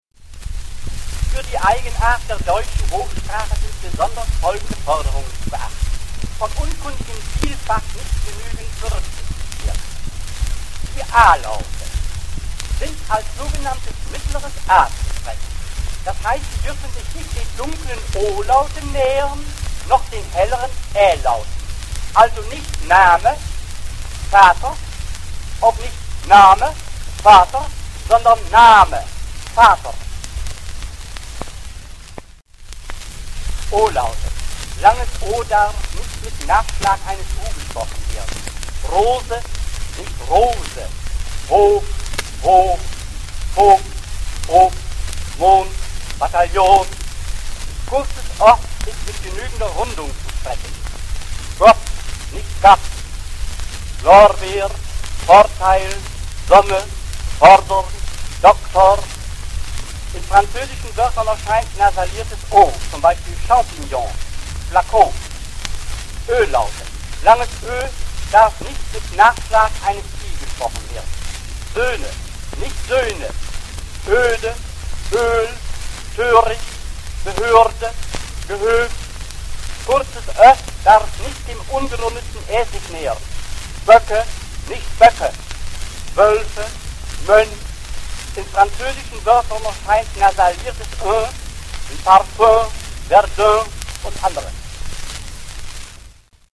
Berlin, 4. Dezember 1925, Humboldt-Universität zu Berlin, Lautarchiv, Inv.-Nr. LA 566 und LA 567, Dauer: 1:36 min.
Dieser Anspruch wird auch in seinem aufgezeichneten Vortrag vom 4. Dezember 1925 mit dem Titel „Die Laute der deutschen Hochsprache“ deutlich. In den Beispielen äußert er sich über die korrekte Aussprache der Laute A, O und Ö. Aus dem Lautarchiv der HU stammen einige Exponate, die in der Ausstellung „Speaking without lips, thinking without brain“ [3] zu sehen und auch zu hören sind.